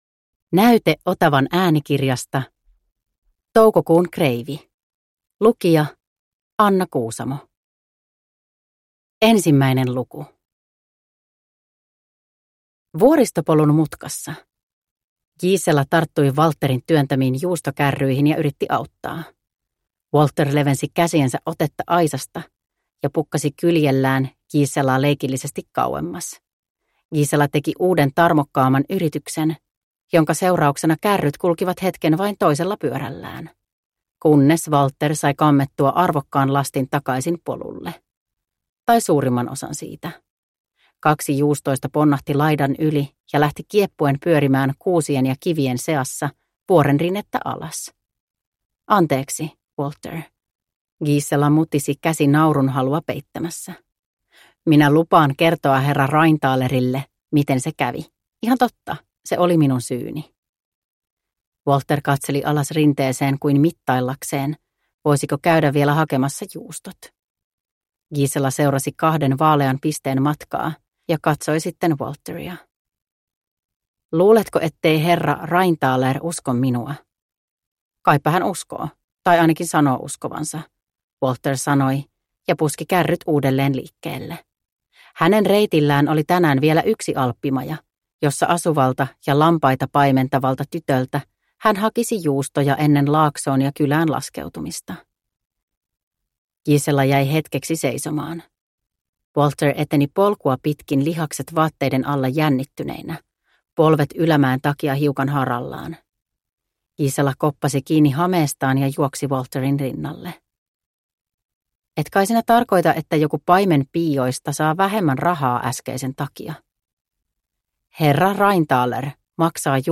Toukokuun kreivi – Ljudbok – Laddas ner